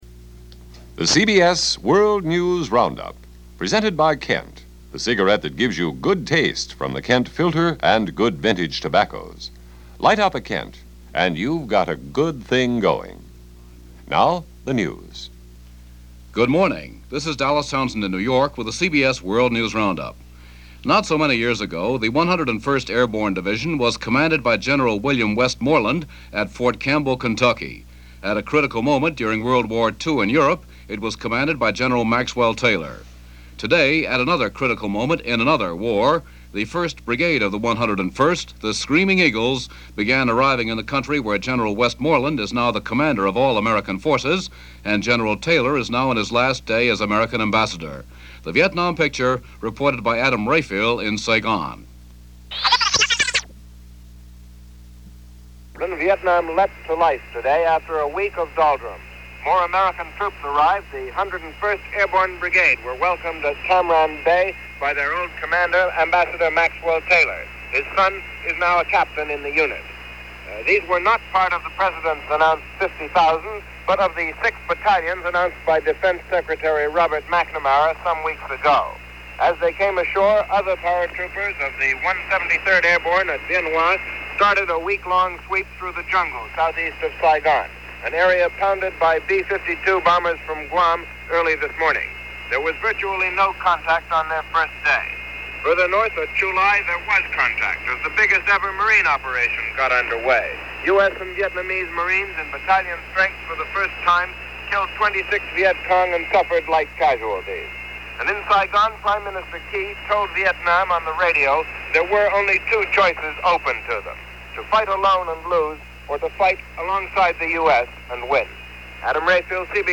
A New Word In Our Lexicon: Medicare - A New Vision In Our Nightmares: Vietnam - July 29, 1965 - CBS World News Roundup.